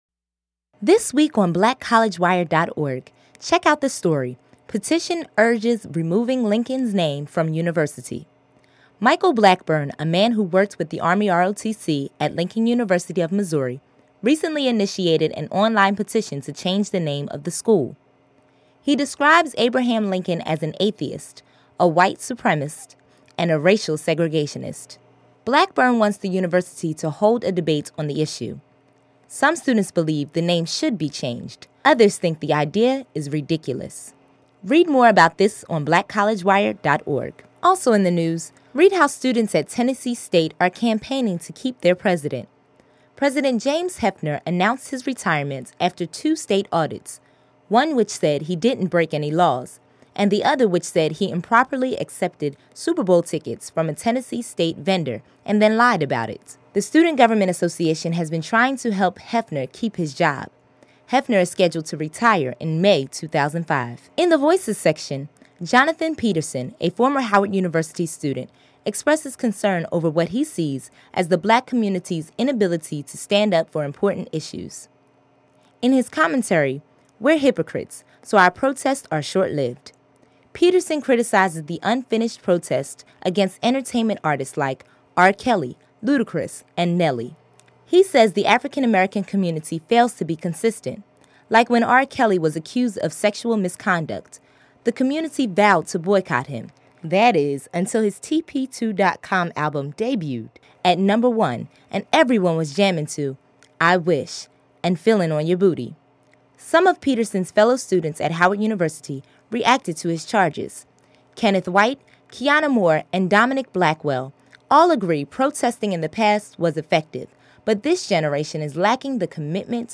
News Summary of the Week